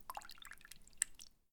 water-burbllrsh
Category 🌿 Nature
bath bathroom bathtub bubble burp drain drip drop sound effect free sound royalty free Nature